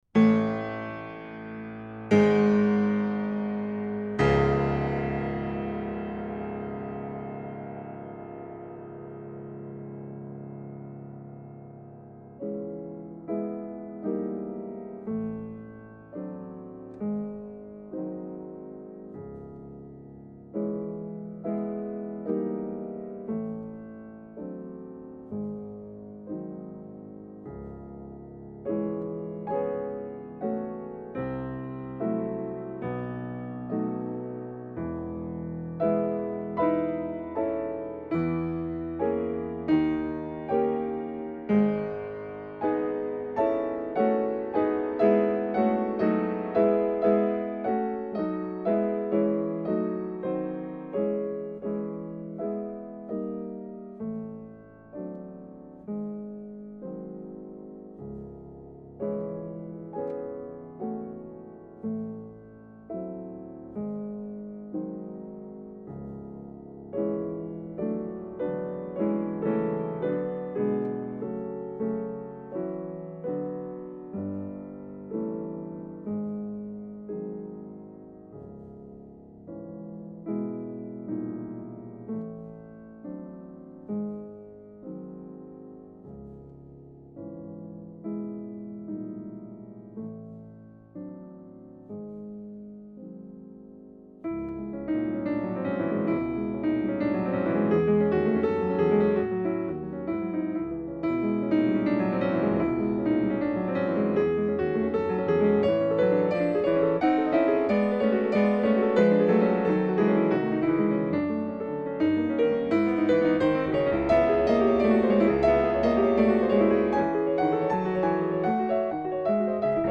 piano), Curitiba: Capela Santa Maria 26/08/08 Sergej Rachmaninov: Prelude opus 3 No. 2 Claude Debussy